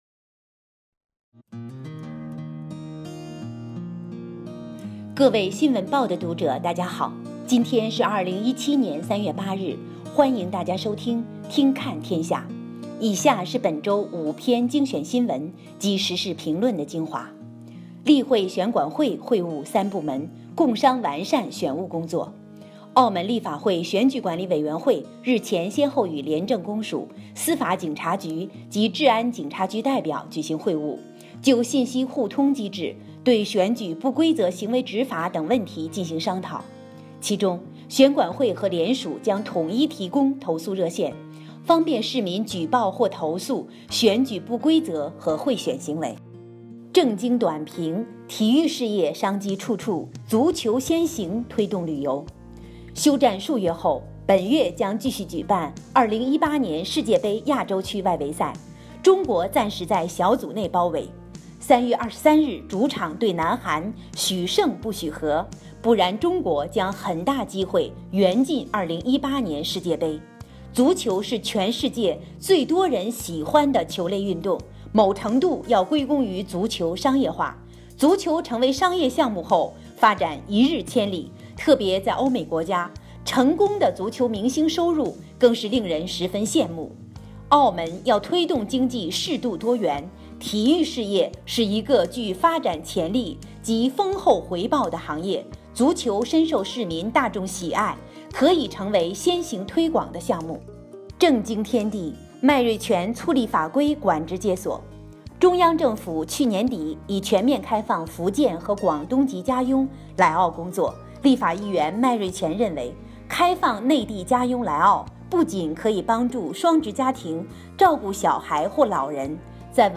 [語音播報]新聞及時事評論精華（普通話）